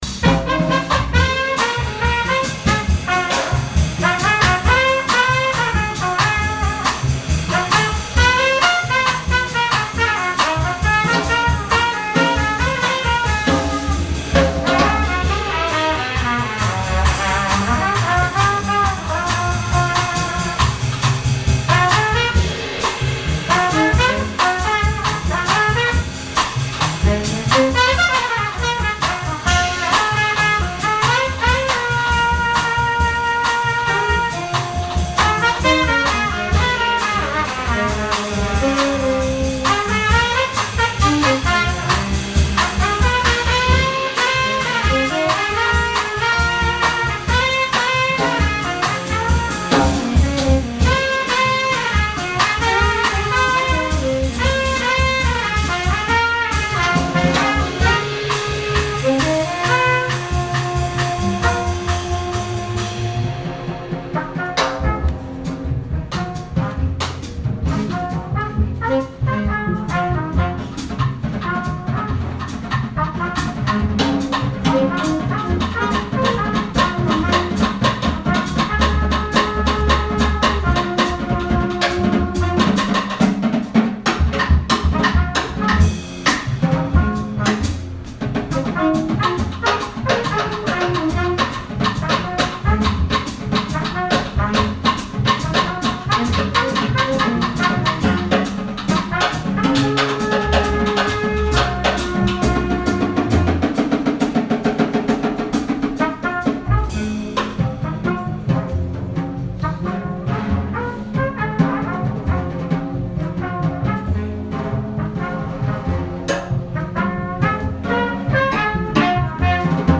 13th August 2017, Washington DC